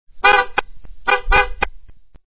Descarga de Sonidos mp3 Gratis: bocina 2.
horn2.mp3